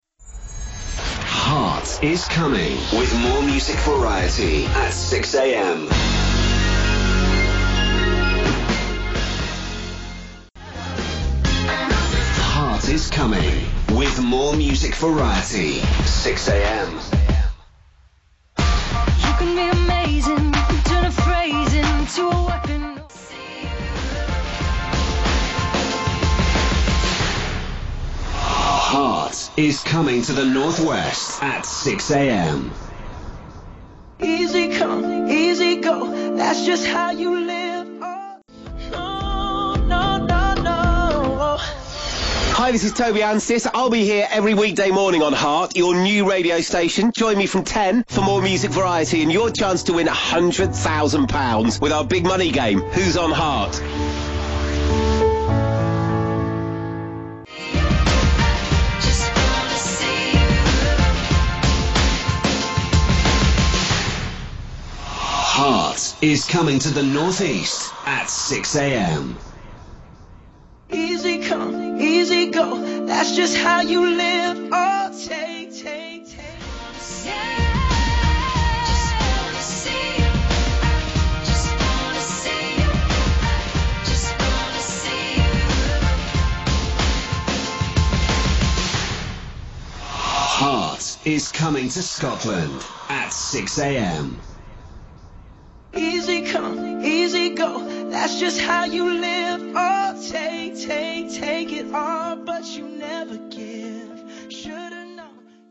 May 2014 - and Heart was coming as it expanded once more - to 9.2m listeners in all. Here, hear the day before on three of the services.